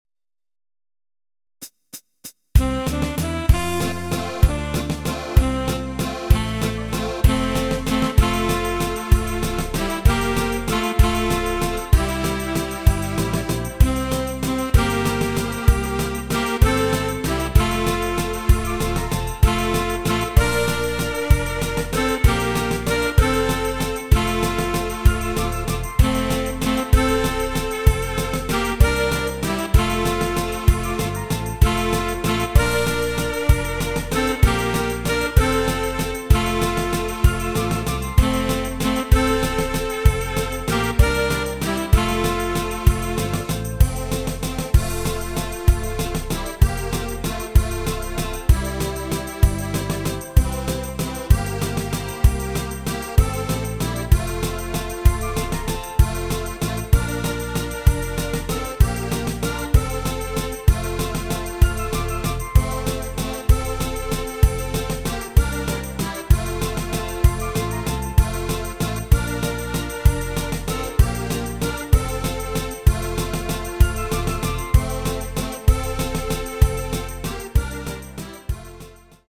Rubrika: Národní, lidové, dechovka
- valčík
HUDEBNÍ PODKLADY V AUDIO A VIDEO SOUBORECH